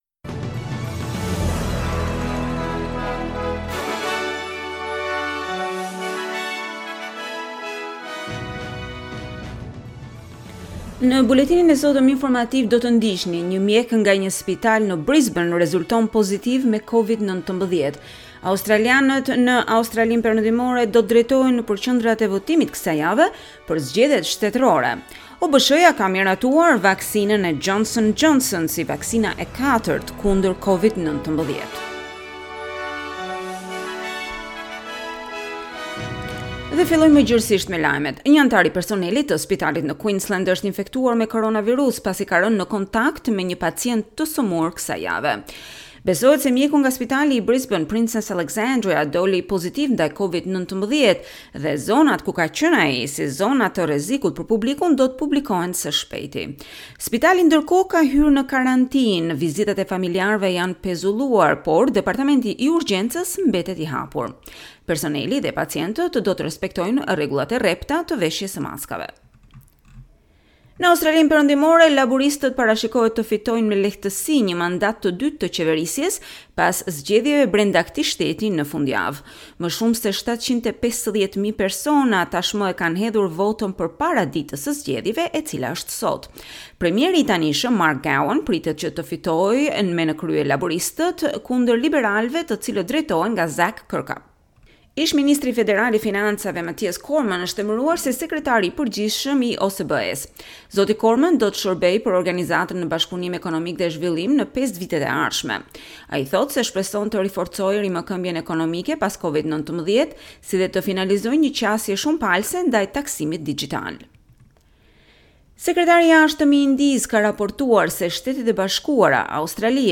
SBS News Bulletin in Albanian - 13 March 2021